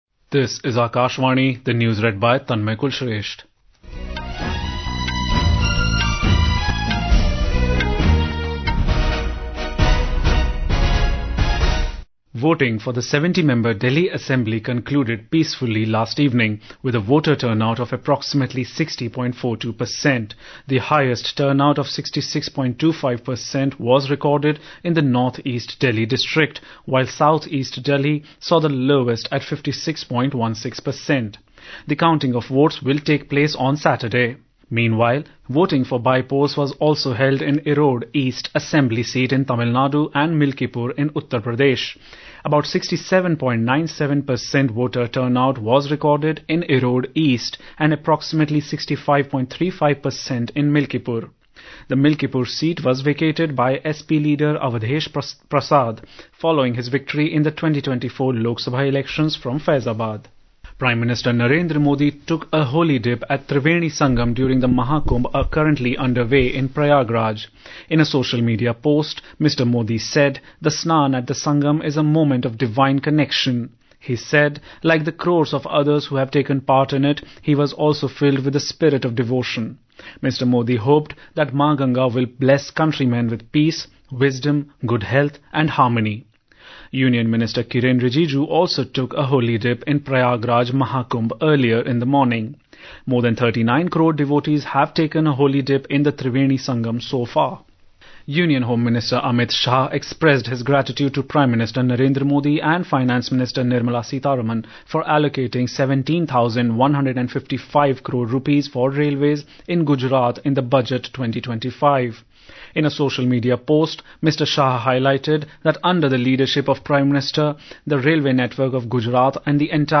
National Bulletins